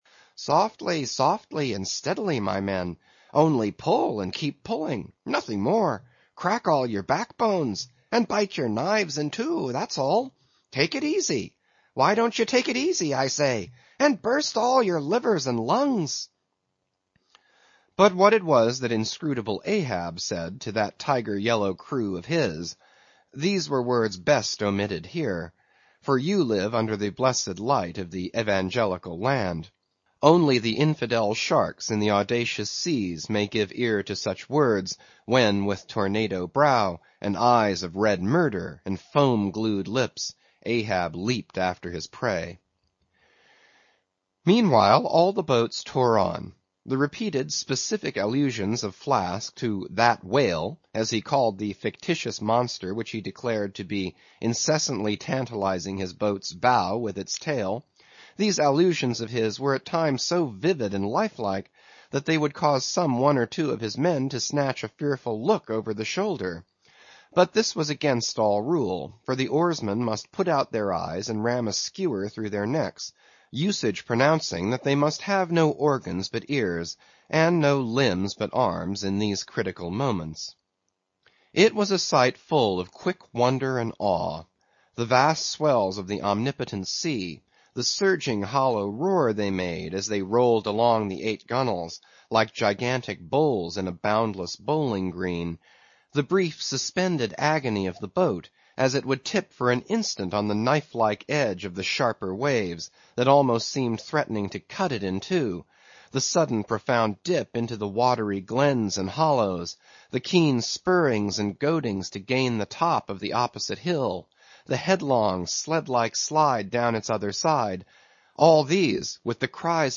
英语听书《白鲸记》第498期 听力文件下载—在线英语听力室